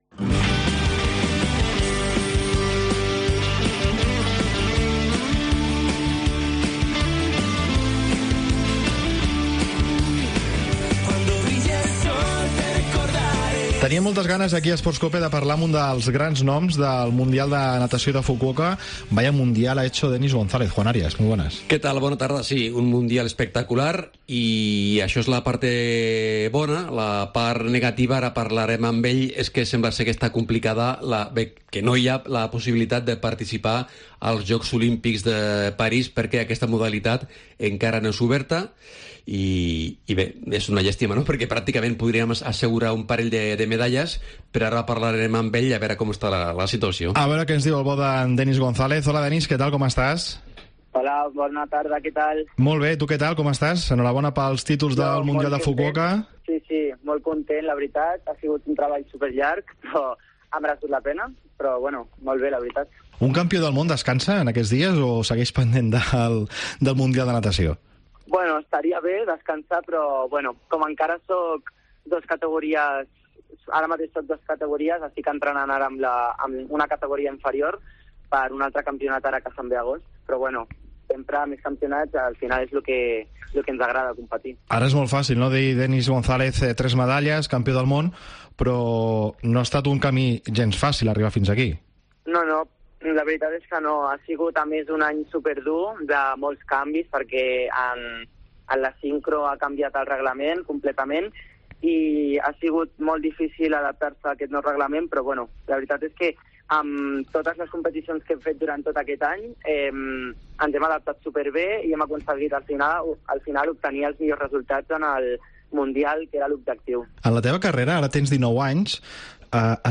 Parlem amb el campió del món en solo lliure en natació artística al Mundial de Fukuoka.